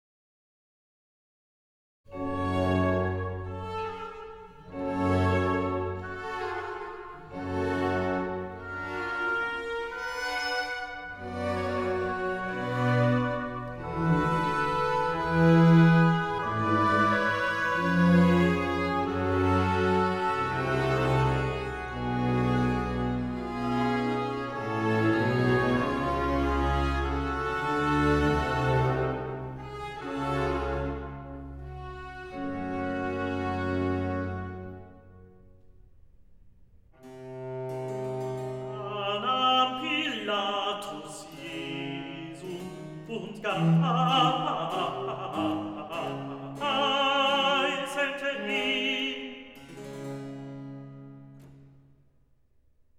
01 - Sinfonia. Recitativo evangelist Da nahm Pilatus Jesum